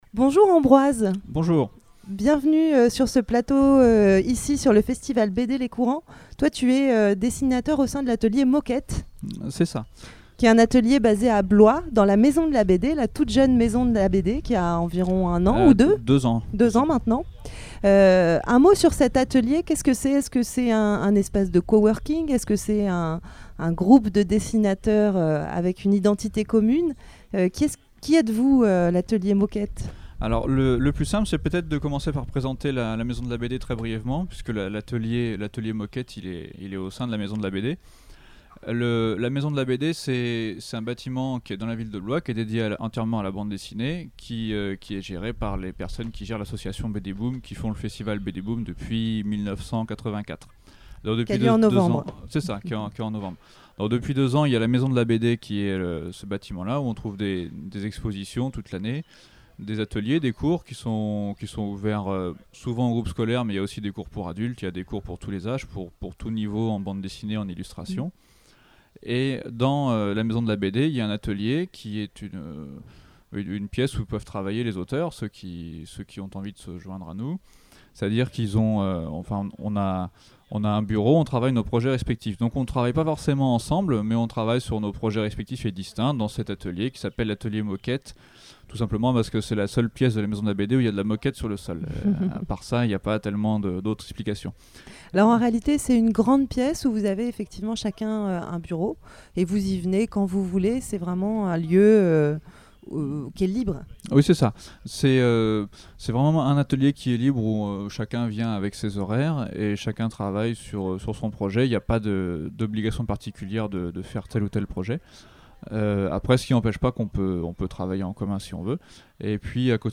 Interview radio: Interview donné lors du festival BD de St Ouen, retrouvez moi à 9:49 s TRAILERS: Voici le fameux Trailer de Blue Ghost Tome 2.